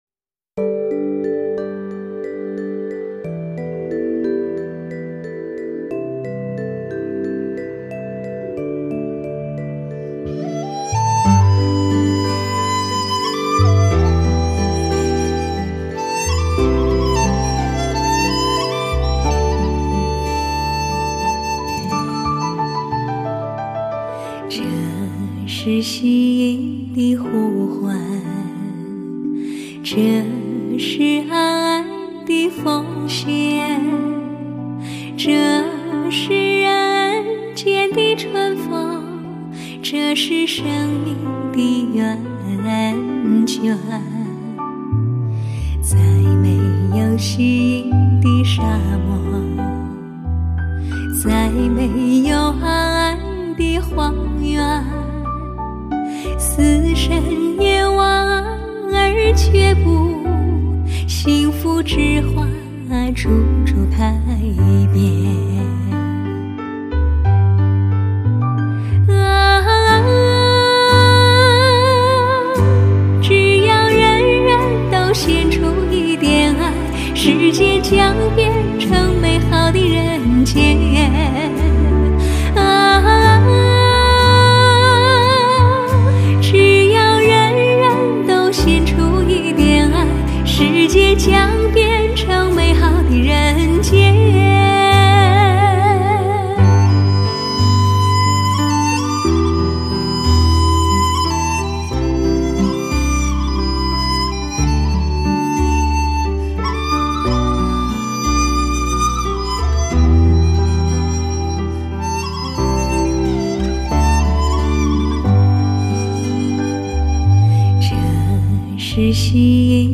黑胶唱片 返璞归真 绝对人声的一次革命
溫柔的歌聲